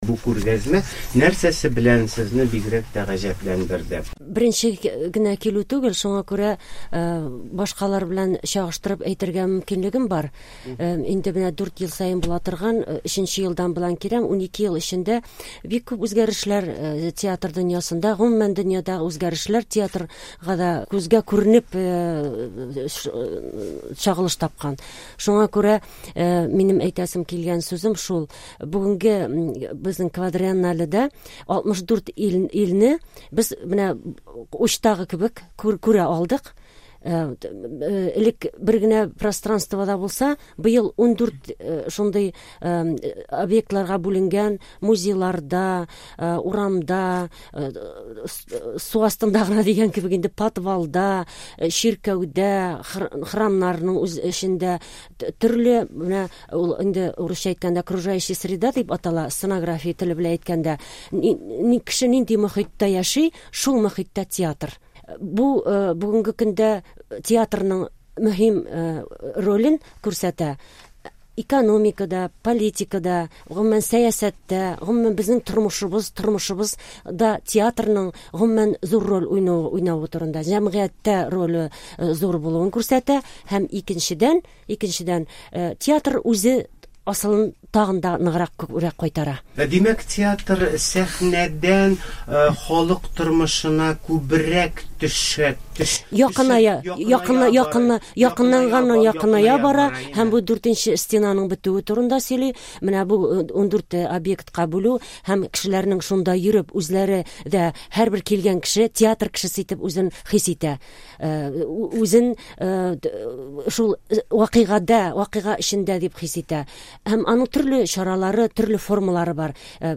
Прагада Prazske Quadriennale фестивале тәмамланды. Азатлык студиясендә ИЯЛИ хезмәткәре